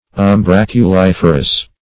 ([u^]m*br[a^]k`[-u]*l[i^]f"[~e]r*[u^]s)